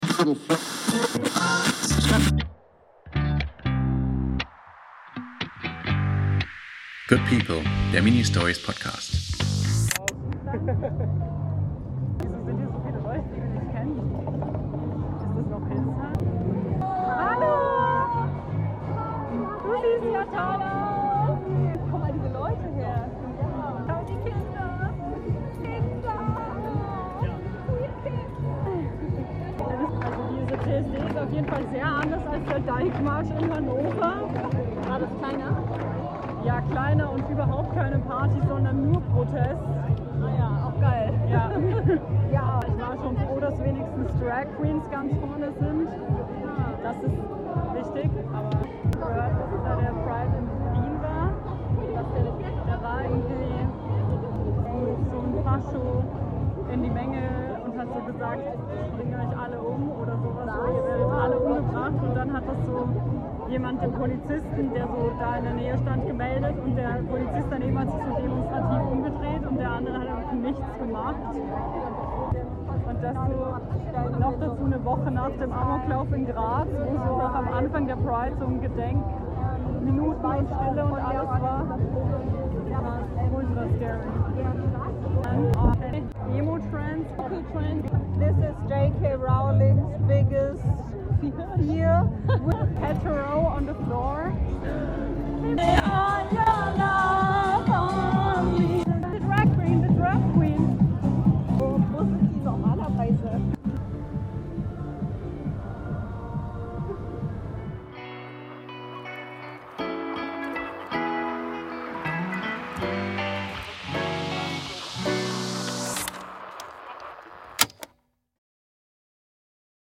Soundcollagierend gehen wir auf den ersten CSD in Hildesheim, wir lesen Statistiken zu transfeindlicher Gewalt, wie immer weinend und dann chanten wir ein Gedicht von ALOK, um uns zu reminden, wie magical und powerful wir trans* people sind.